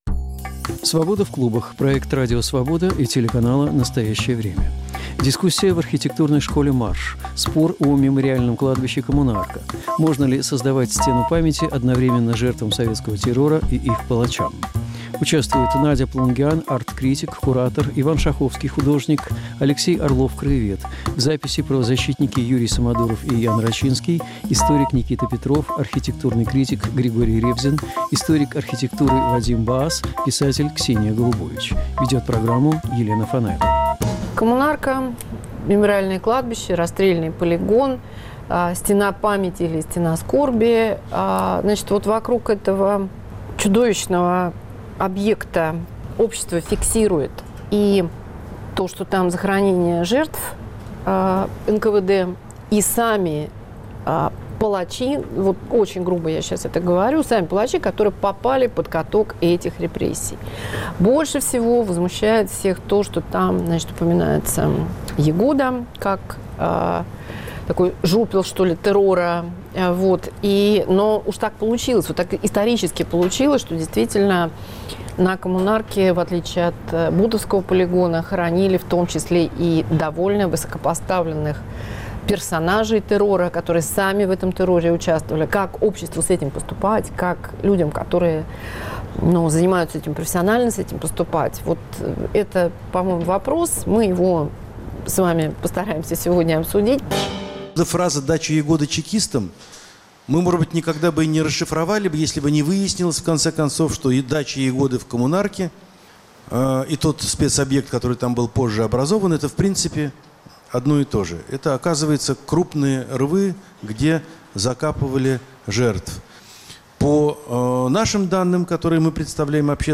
Что нам делать с Коммунаркой? Дискуссия о Стене памяти на расстрельном кладбище, где лежат жертвы и палачи советских репрессий.